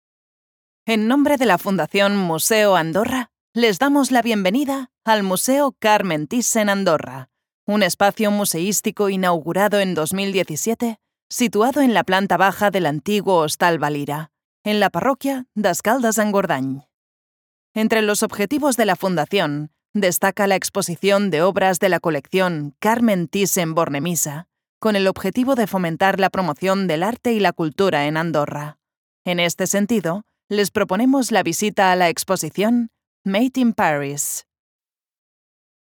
Kommerziell, Natürlich, Freundlich, Warm, Sanft
Audioguide